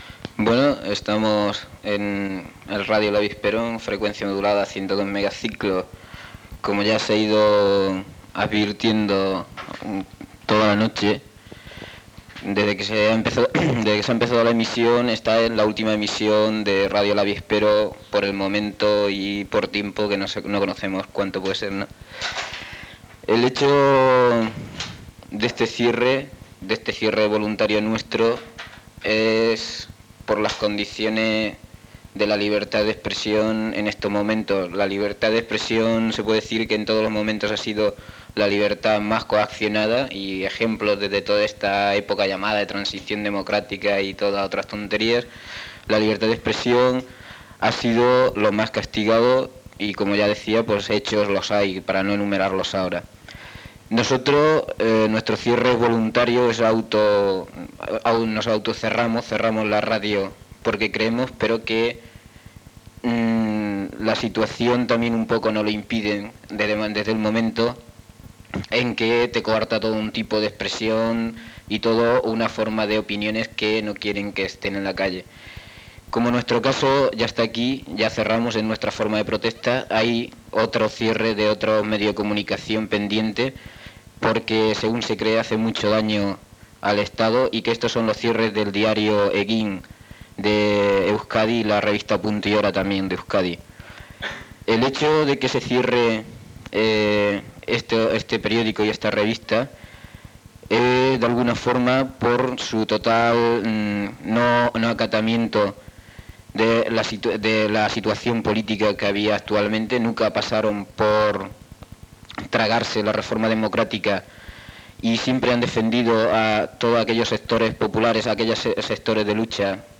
Identificació, reflexió sobre la llibertat d'expressió a la premsa i cançó.
Informatiu
FM
Últim dia d'emissió.